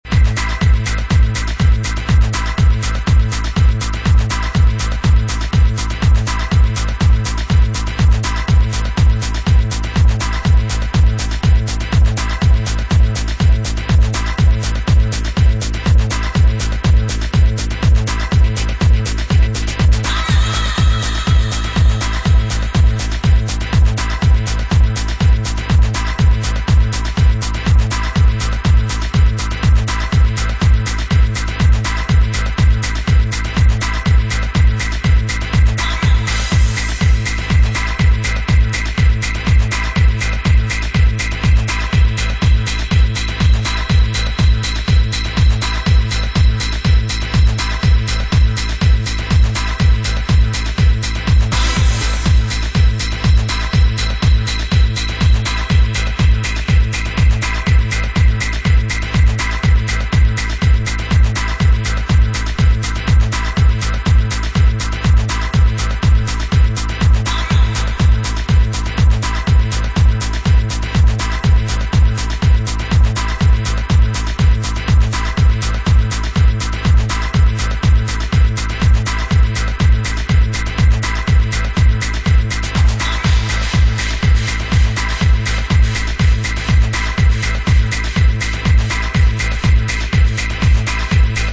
Uptempo club stormer
Two tracks that fit in the 90's style